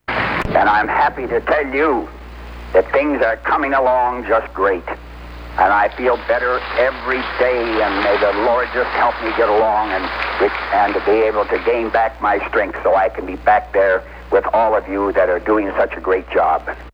Humphrey's telephone messages to friends gathered in Washington in his honor